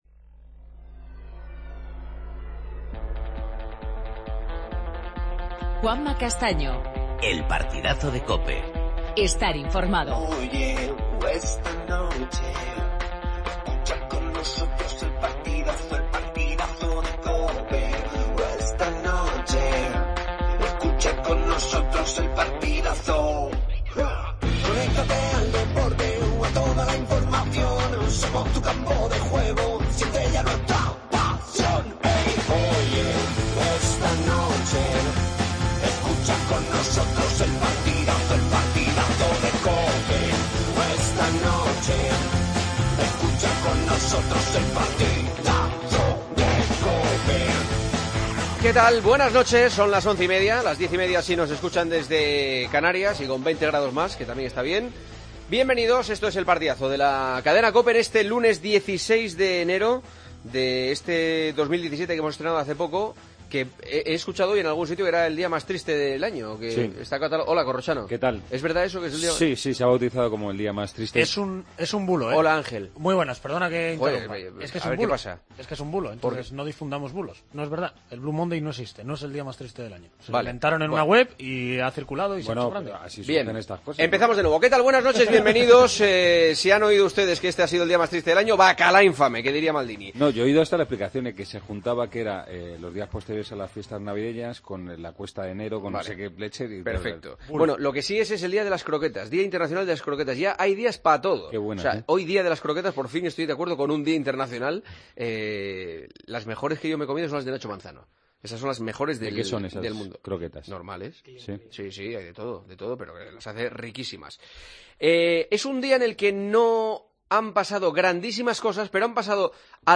Entrevista al tenista Nico Almagro.